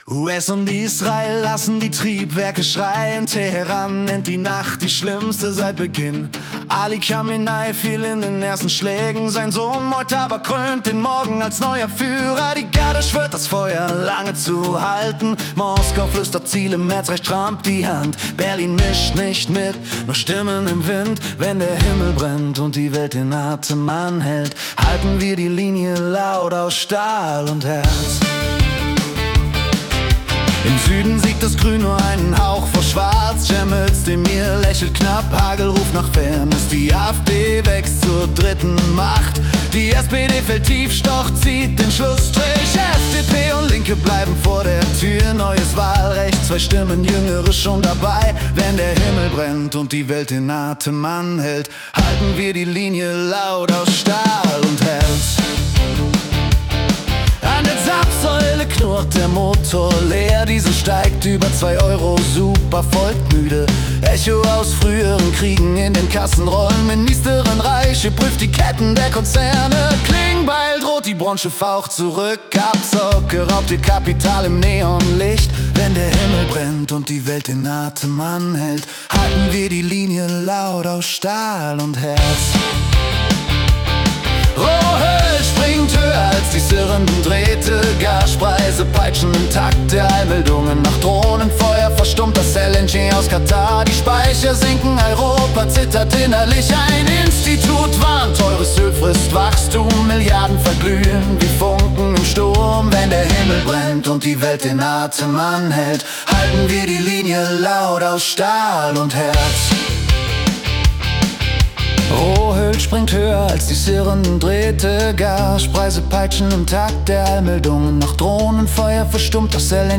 Die Nachrichten vom 9. März 2026 als Rock-Song interpretiert.
Jede Folge verwandelt die letzten 24 Stunden weltweiter Ereignisse in eine mitreißende Rock-Hymne. Erlebe die Geschichten der Welt mit fetzigen Riffs und kraftvollen Texten, die Journalismus...